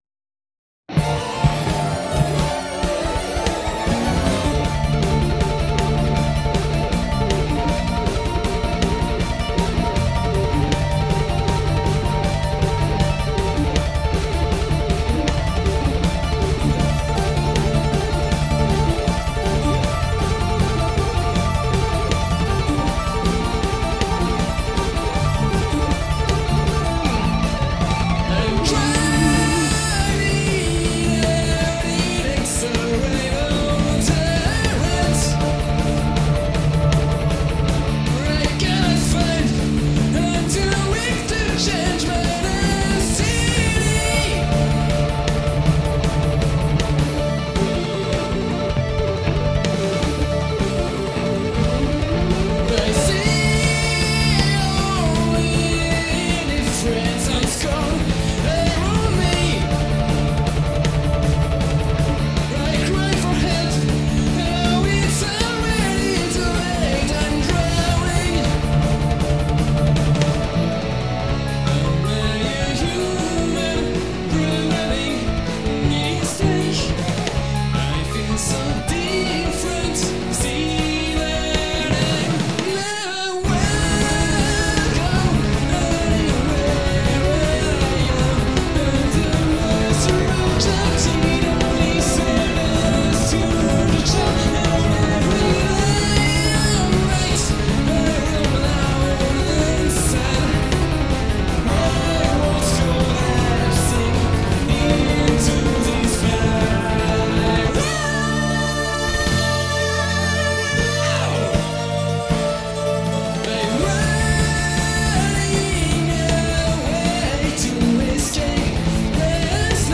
(je sais pas si c'est mieux que ma version d'avant... en tout cas je trouve toujours ma voix aussi pourrave)
A la limite ton premier enregistrement était meilleur car sur ces mêmes notes ta voix ne paraissait pas avoir tant de tension.